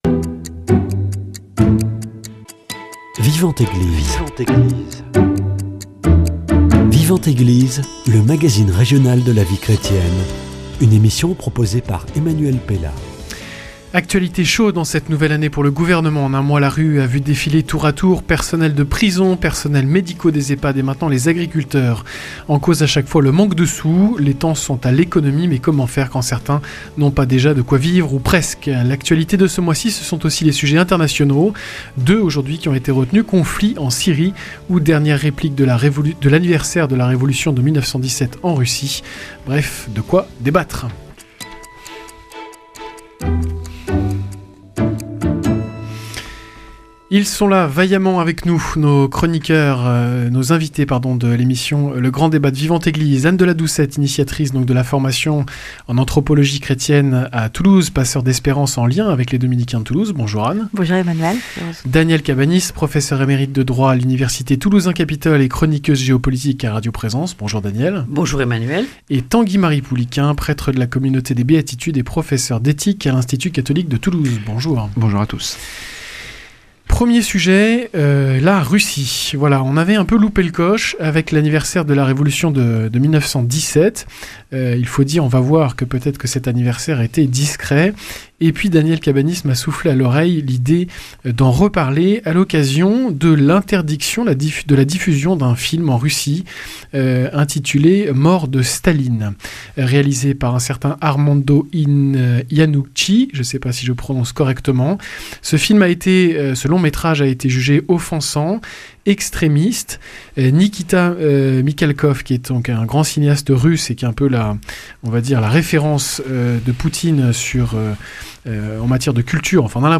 Le grand débat